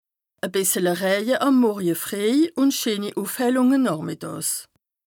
Bas Rhin
Ville Prononciation 67